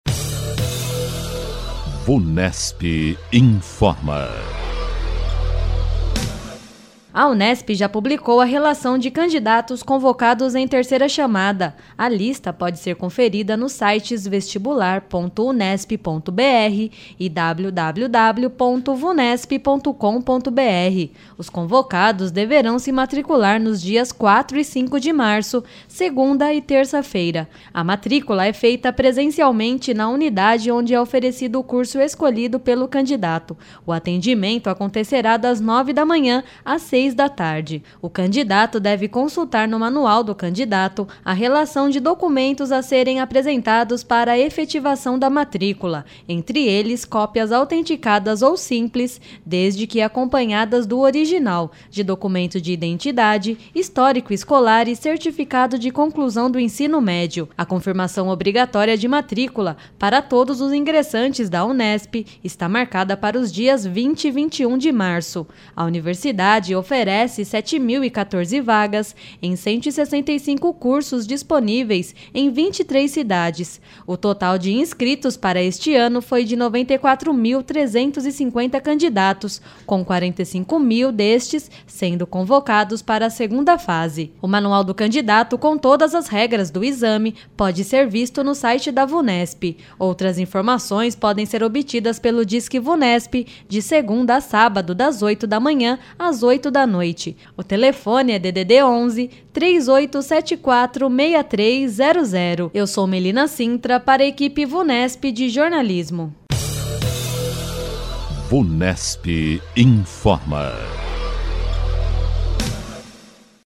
A equipe de jornalistas da Vunesp apresenta as últimas informações sobre concursos, vestibulares e avaliações feitas pela instituição.